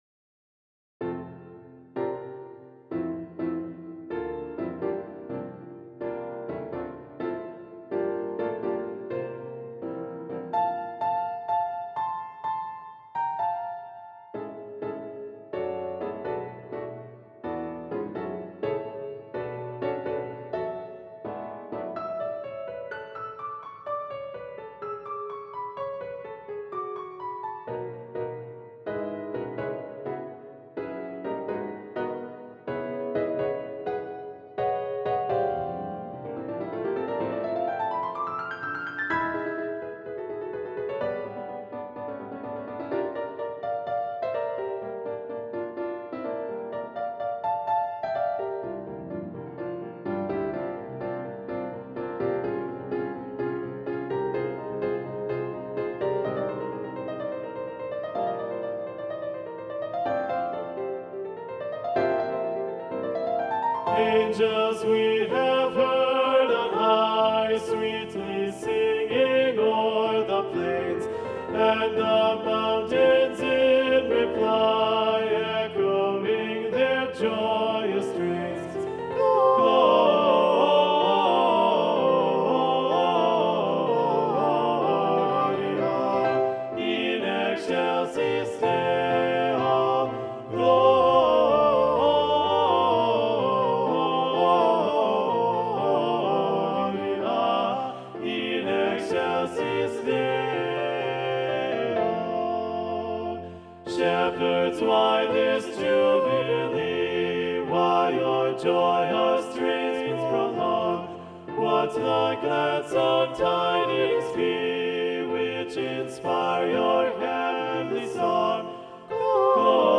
Video Only: Angels We Have Heard on High - Balanced Voices